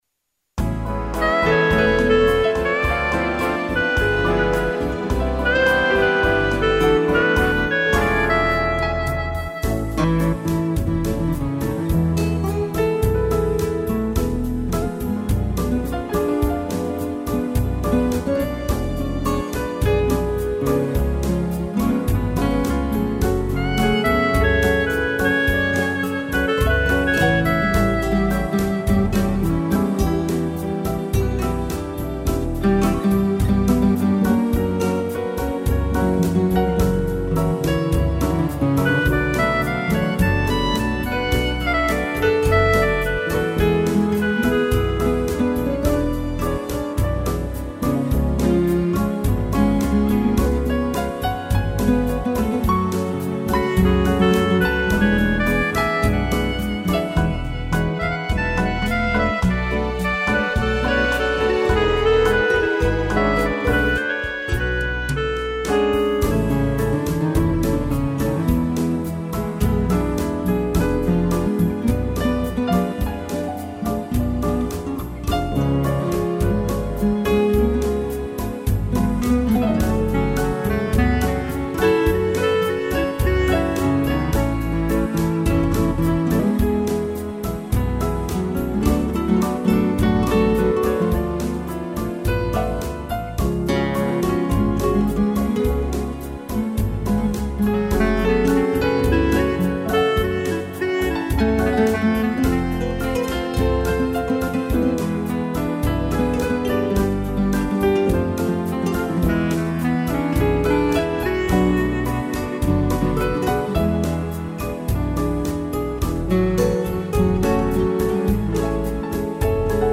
violão
piano e clarinete
instrumental